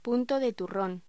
Locución: Punto de turrón
voz
Sonidos: Voz humana